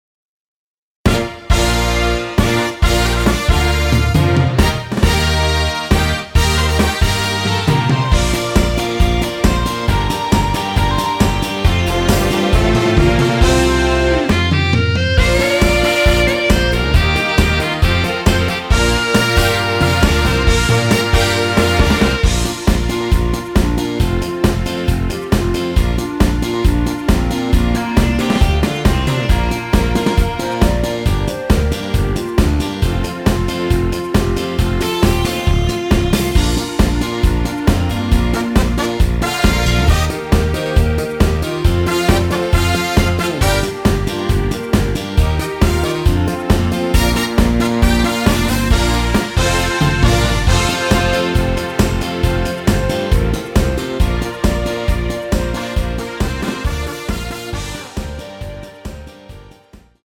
Bb
앞부분30초, 뒷부분30초씩 편집해서 올려 드리고 있습니다.